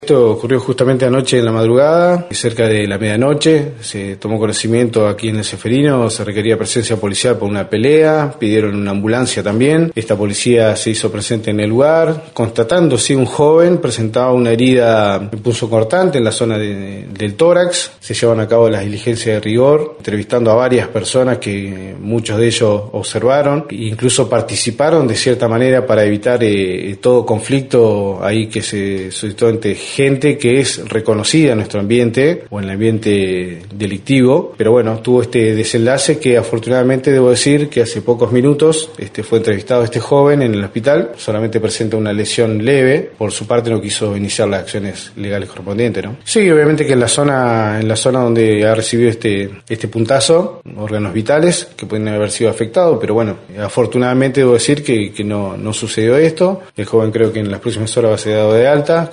Más adelante el Comisario informó sobre el caso de un joven que fue herido de arma blanca en el pecho.